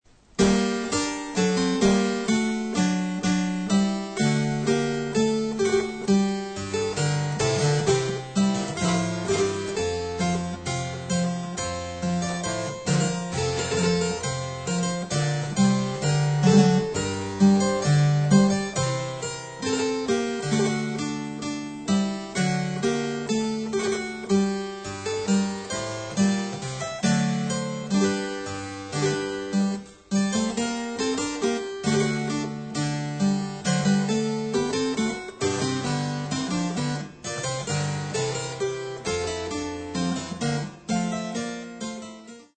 Le "Muselar" est un virginal qui a le clavier à droite, le pincement est donc proche du milieu des cordes, cela donne un son très rond dans les aigus, et carrément "ronflant" dans les bases.
Vous pouvez écouter le muselar avec la mère seulement dans un extrait de Spanish Paven de J. Bull en cliquant ici.